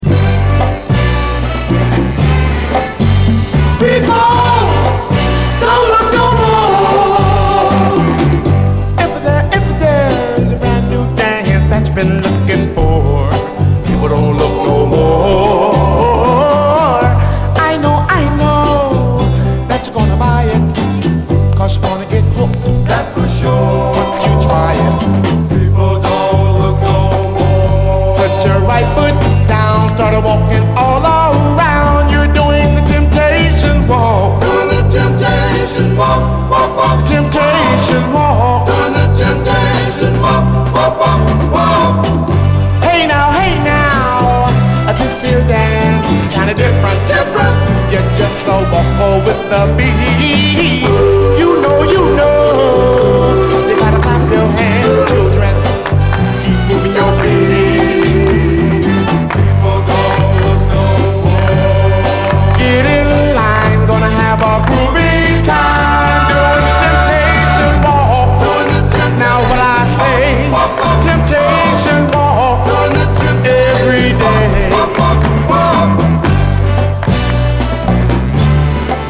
SOUL, 60's SOUL, 7INCH
キャッチー＆ラヴリーな60'Sグレイト・ハーモニック・ソウル45！